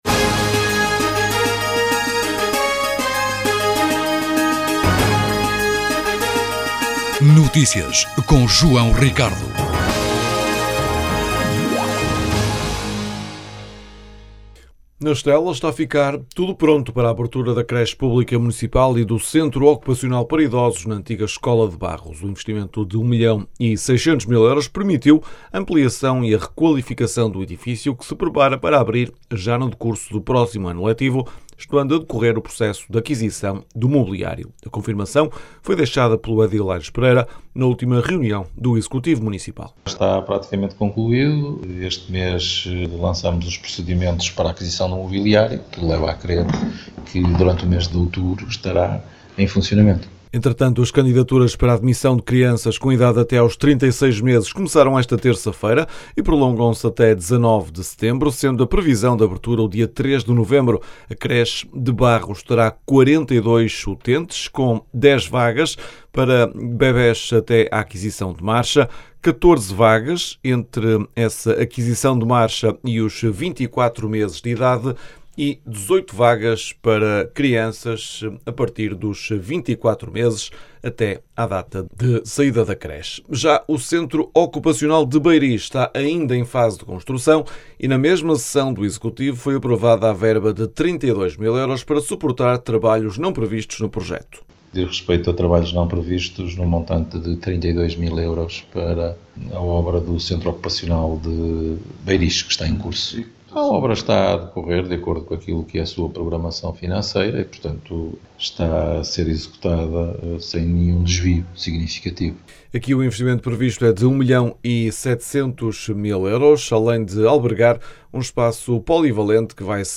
A confirmação foi deixada pelo edil Aires Pereira na última reunião do executivo municipal.
As declarações podem ser ouvidas na edição local.